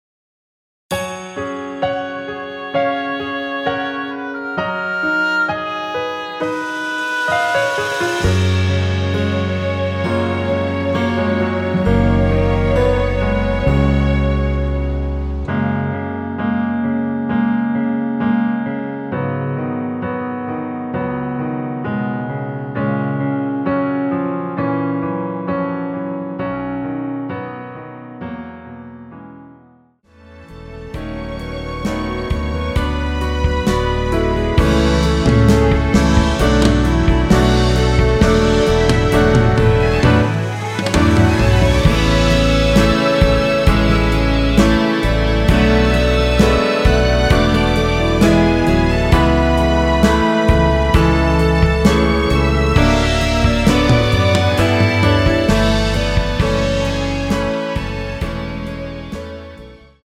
원키에서(-4)내린 1절 + 후렴으로 편곡한 멜로디 포함된 MR 입니다.(미리듣기및 가사 참조)
F#
앞부분30초, 뒷부분30초씩 편집해서 올려 드리고 있습니다.
중간에 음이 끈어지고 다시 나오는 이유는